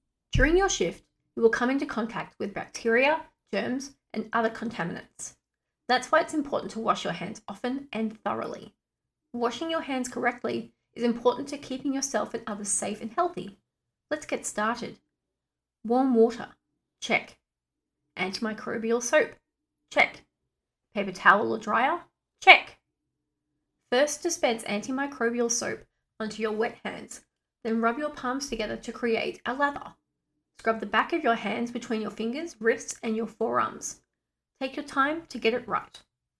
Educational, Approachable, Friendly Female Voice Over Talent
VOICE ACTOR DEMOS
Young American Accent Character